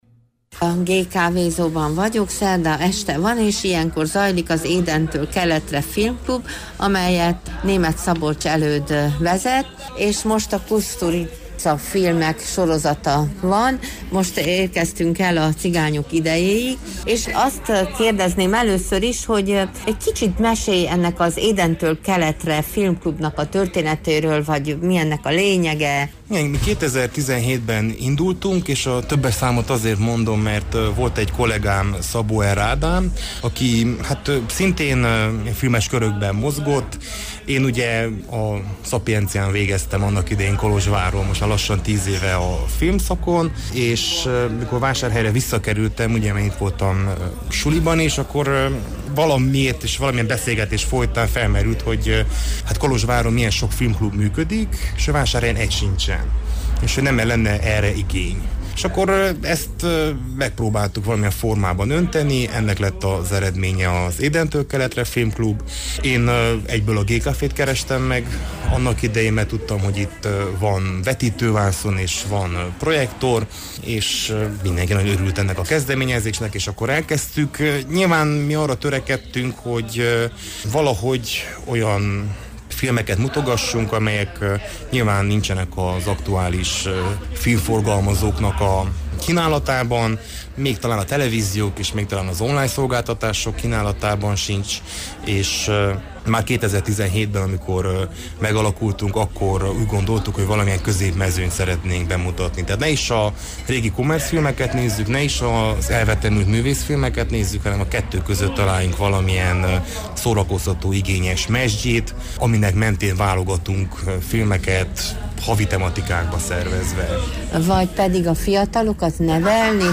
Ezt az interjút hallhatták a kedves hallgatók a november másodikai műsorban. https